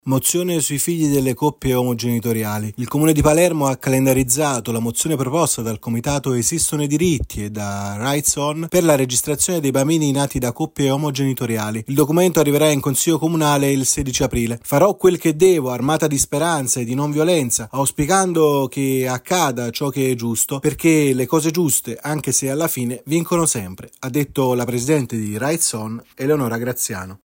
A Palermo si discuterà e voterà la mozione comunale proposta dal comitato Esistono i Diritti  Transpartito per la trascrizione dei figli delle famiglie arcobaleno. Il servizio